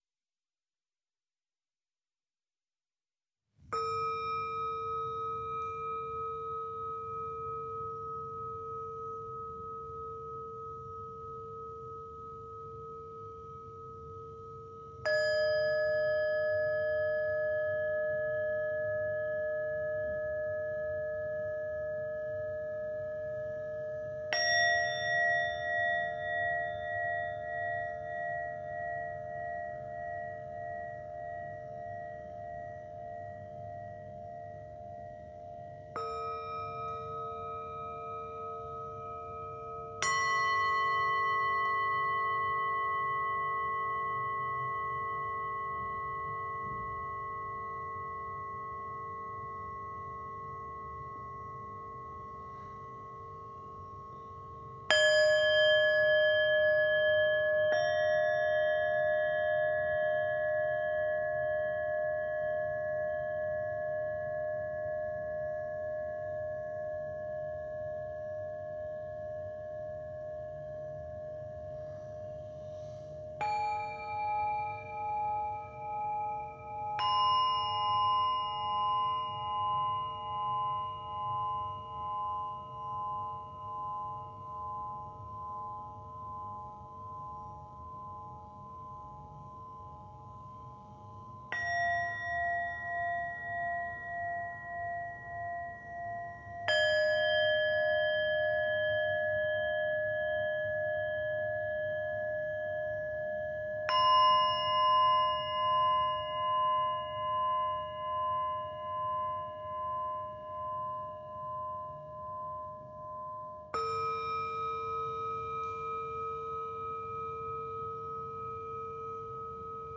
Japanische Klangschalen